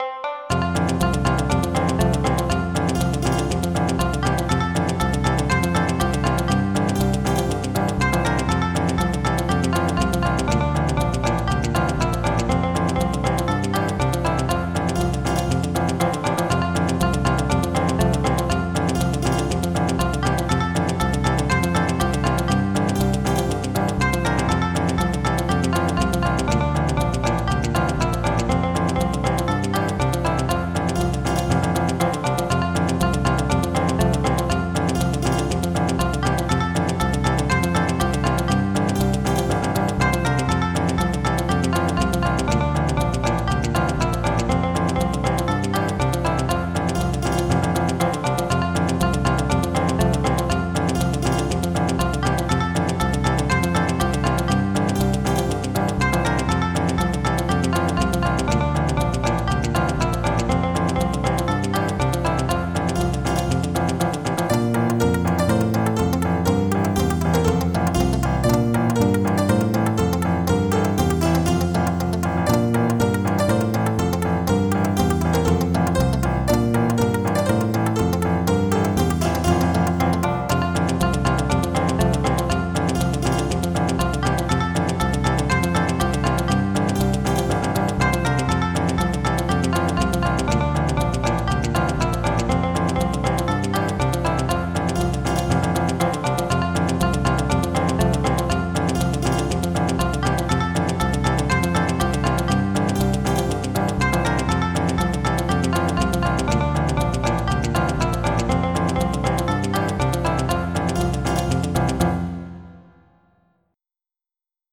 < prev next > MIDI Music File
54 seconds Type General MIDI